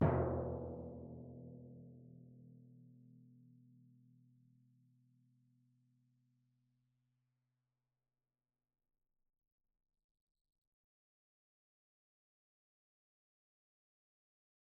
Timpani Large
Timpani1C_hit_v3_rr2_main.mp3